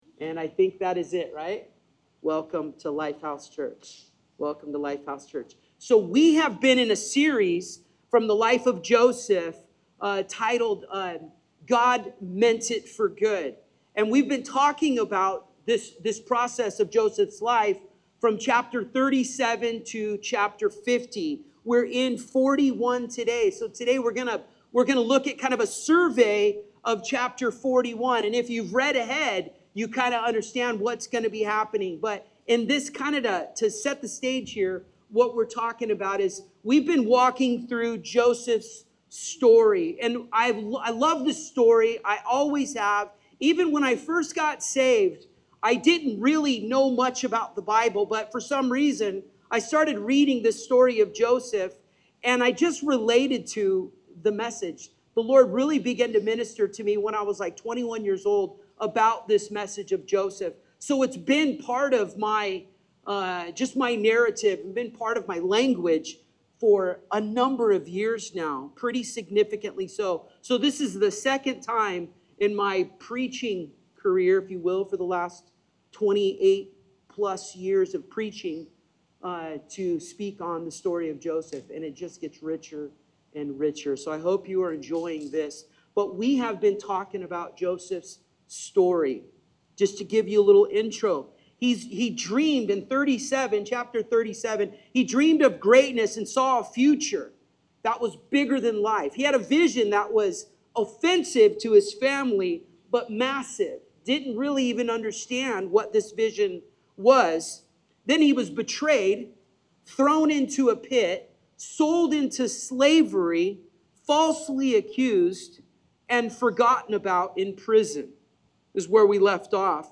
Sermon Series: God Meant It for Good — The Journey of Joseph